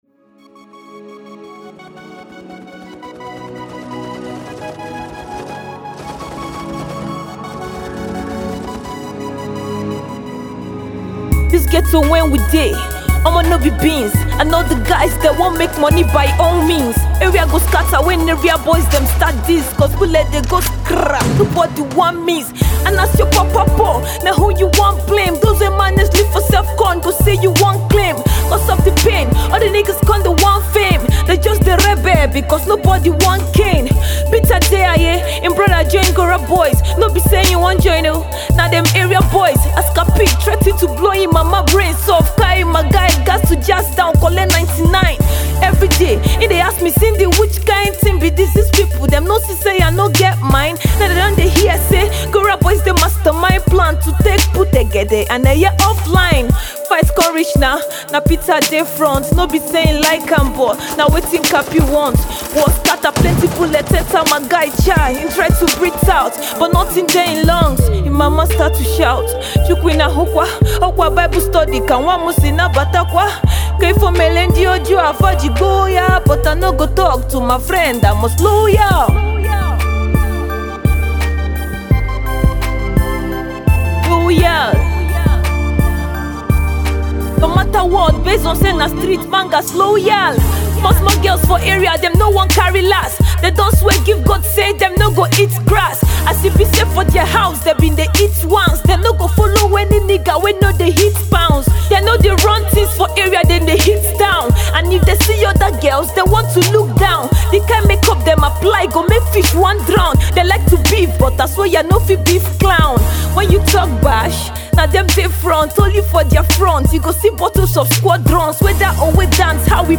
rap song